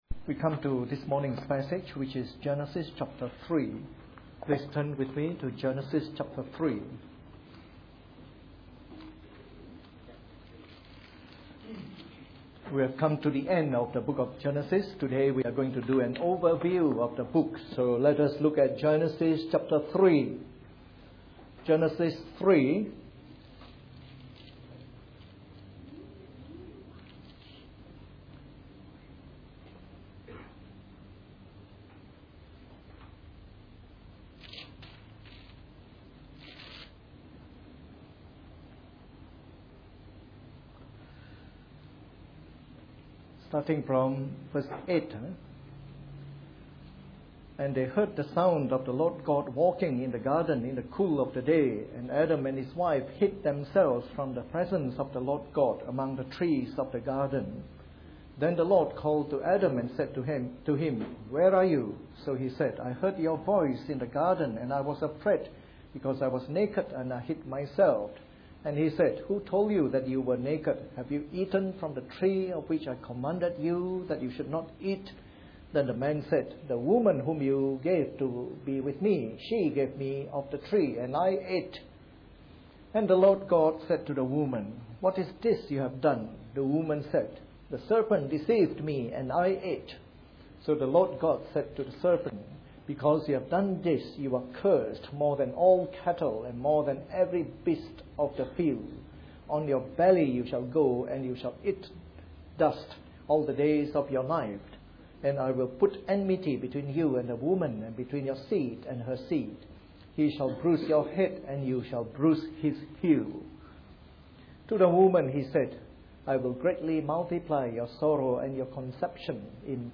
This concludes the morning service series on the Book of Genesis.